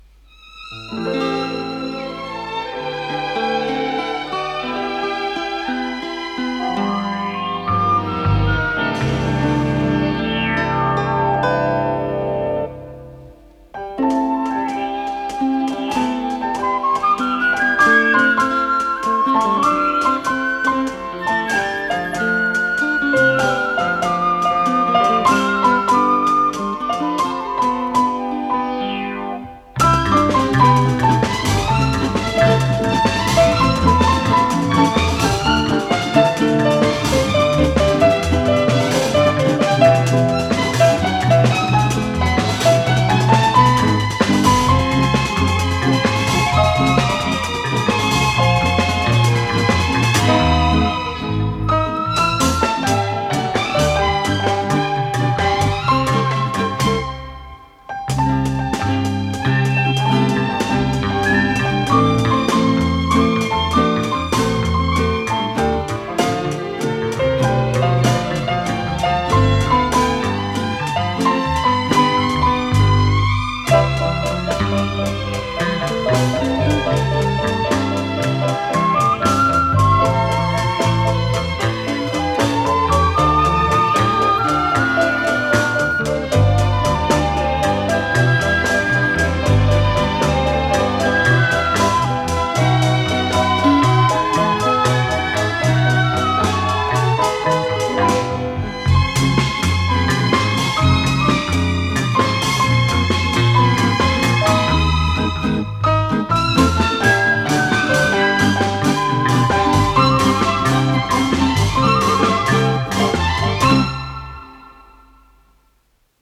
с профессиональной магнитной ленты
ПодзаголовокЗаставка, до мажор
ВариантДубль моно